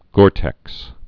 (gôrtĕks)